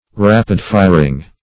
Rapid-fire \Rap"id-fire`\, Rapid-firing \Rap"id-fir`ing\, a.